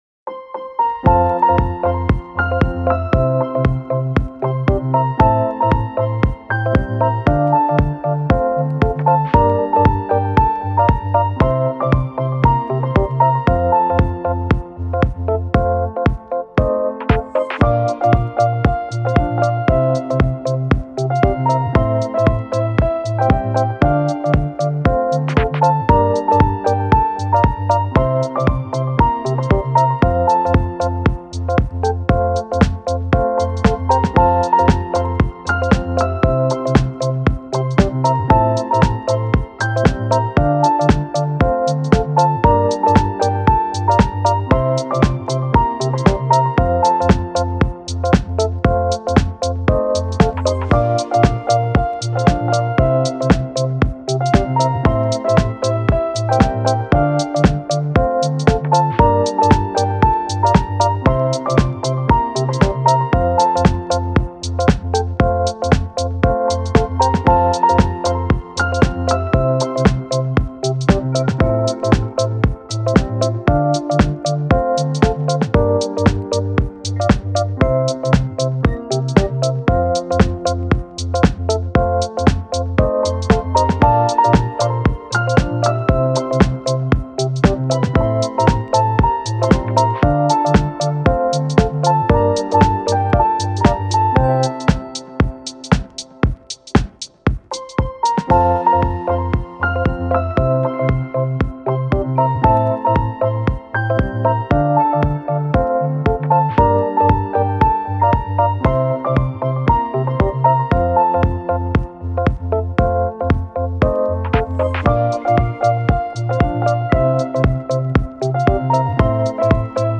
チルポップ , ローファイ , 癒しの空間 , 軽快